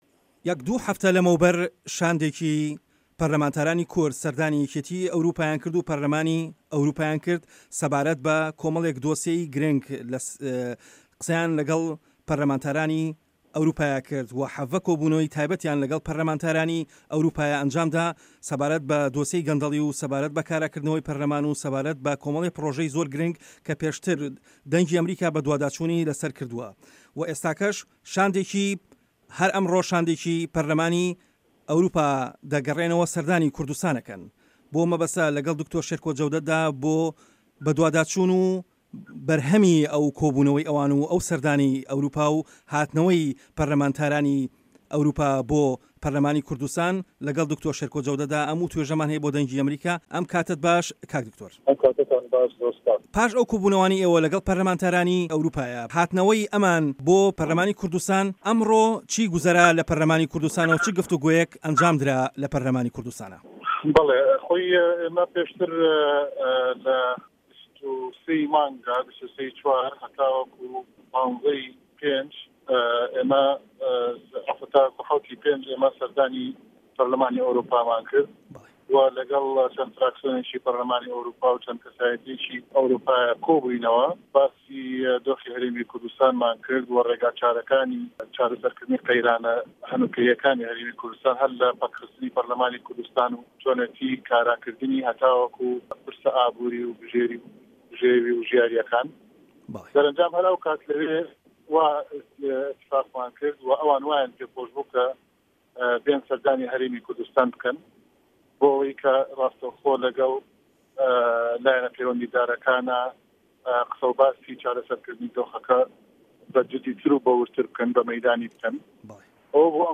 وتووێژ لەگەڵ شێرکۆ جەودەت